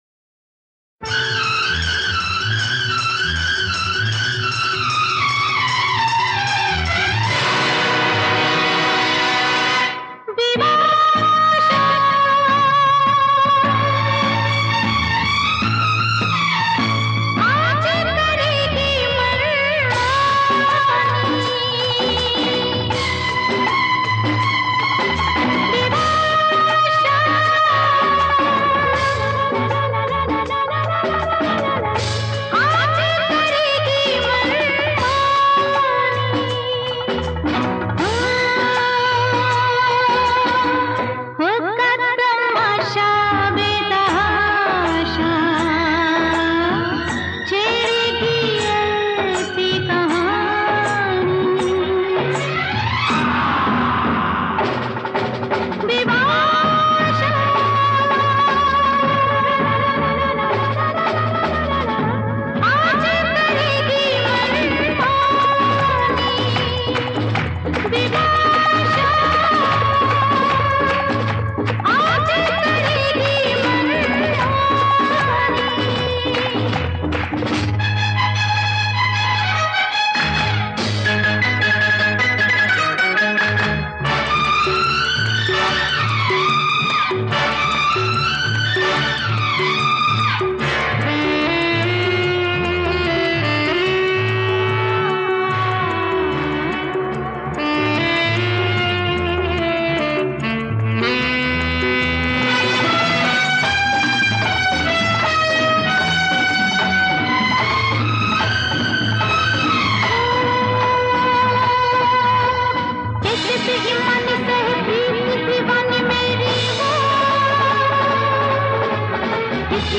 Hindi Movie